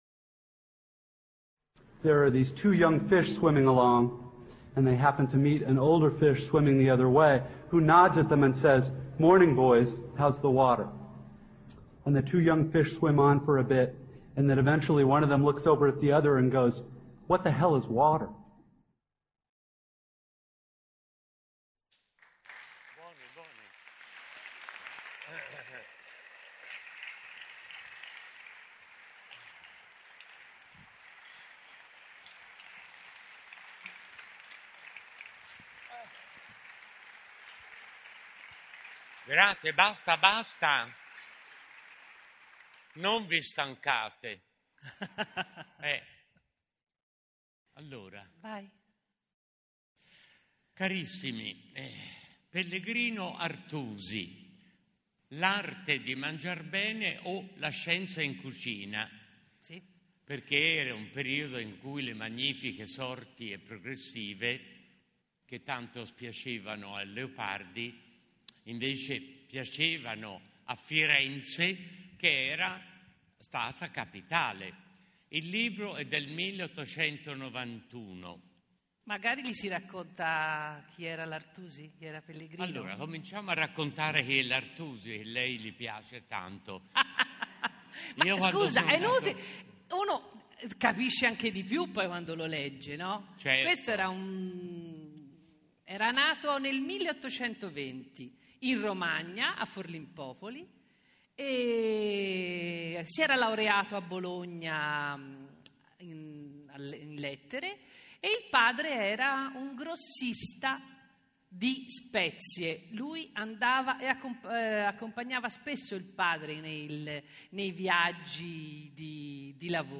L’ultima registrazione dal festival sonoro della letteratura Questa è l’acqua di Reggio Emilia riguarda La scienza in cucina e l’arte di mangiar bene di Pellegrino Artusi: l’hanno letto e ne hanno parlato (il 20 dicembre 2015) Paolo Poli e Luisanna Messeri.